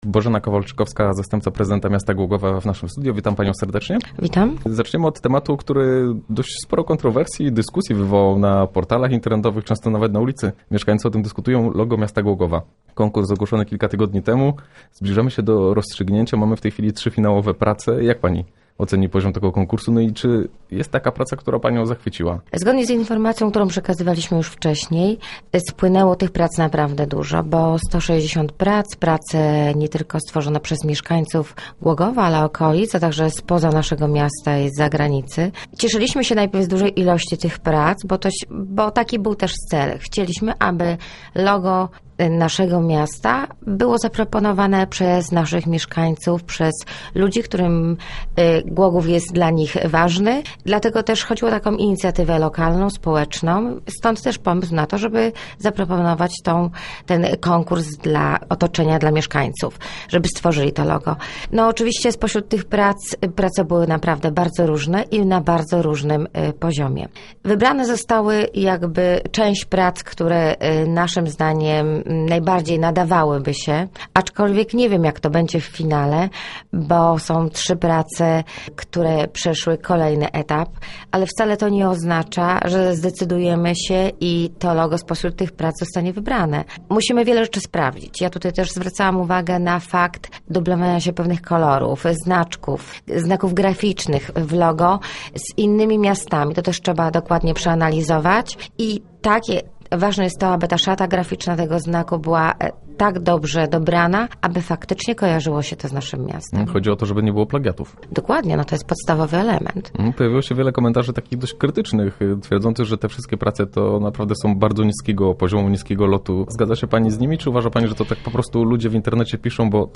W jej składzie jest między innymi zastępca prezydenta Bożena Kowalczykowska. Podczas spotkania w studio rozmawialiśmy między innymi o konkursie, który wzbudził wiele komentarzy i kontrowersji.